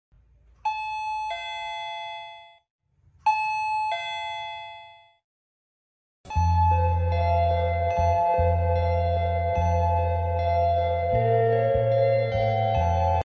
Doorbell sound sound effects free download